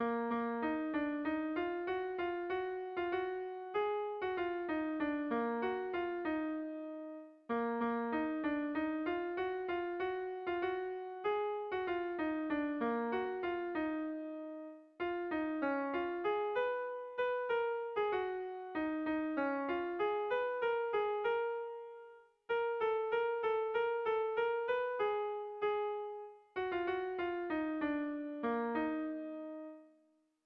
Zortziko handia (hg) / Lau puntuko handia (ip)
AABD